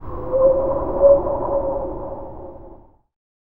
TUV NOISE 02.wav